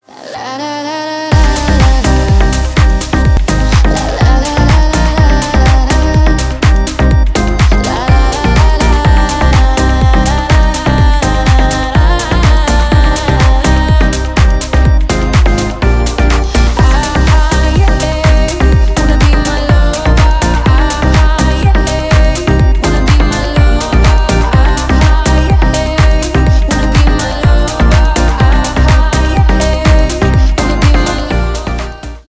клубные
house